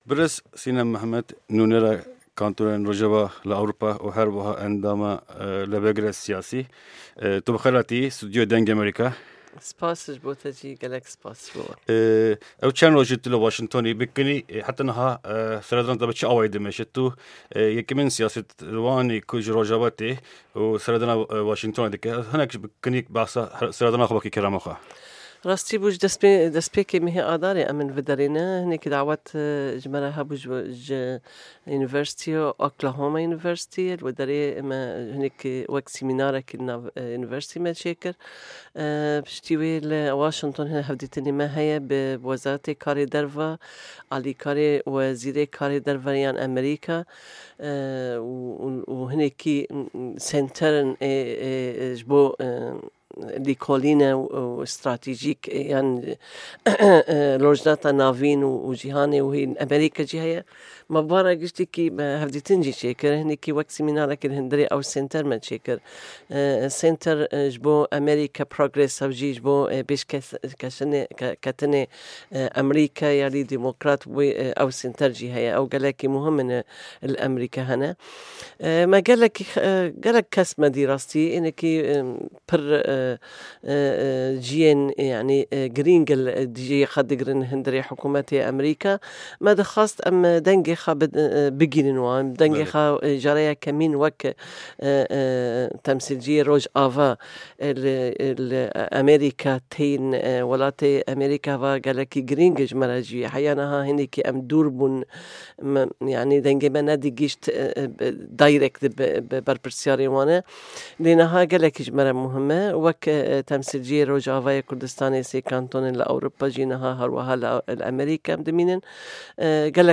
Hevpeyvîneke Taybet